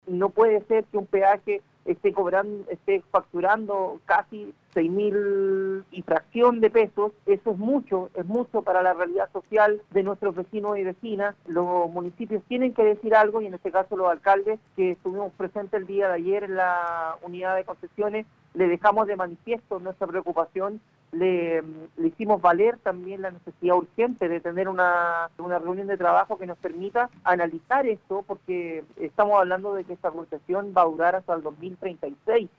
El alcalde de Hijuelas, José Saavedra, quien concedió una entrevista en Radio La Calera Observador, entregó detalles de la reunión sostenida el lunes 10 de julio con varios jefes comunales de la provincia, quienes viajaron hasta Santiago para reunirse con el director general de concesiones, José Manuel Sánchez, en la unidad de concesiones del MOP.
Alcalde-de-Hijuelas-Jose-Saavedra.mp3